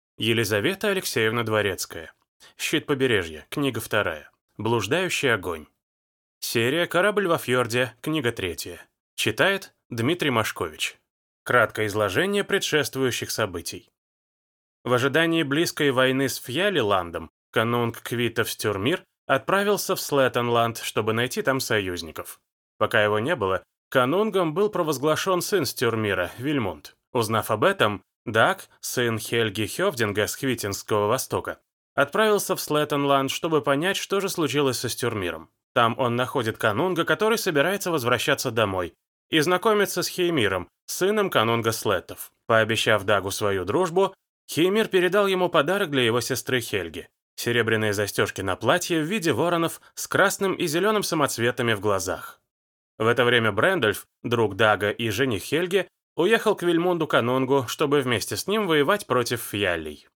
Аудиокнига Щит побережья. Книга 2: Блуждающий огонь | Библиотека аудиокниг